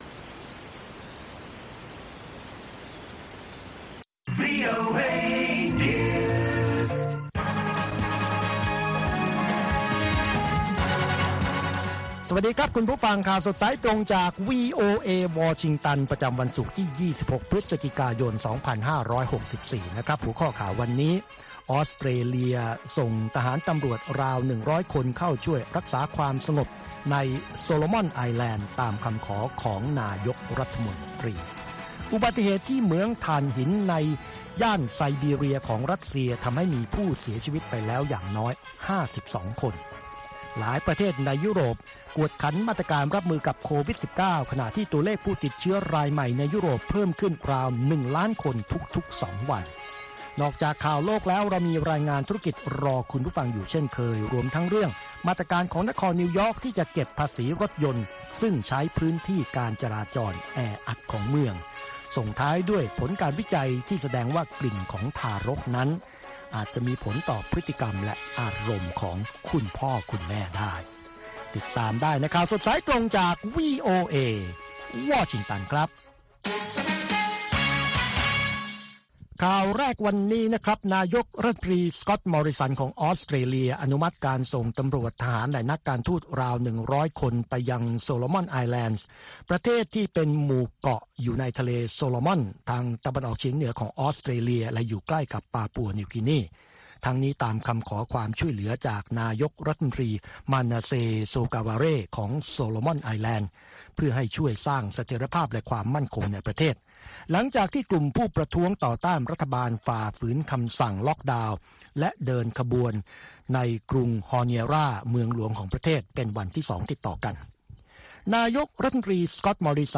ข่าวสดสายตรงจากวีโอเอ วันศุกร์ที่ 26 พฤศจิกายน 2564